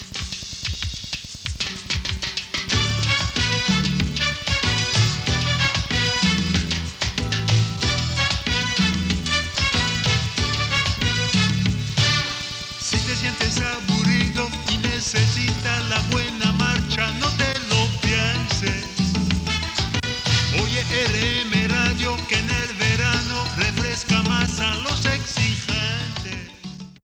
Jingle d'estiu.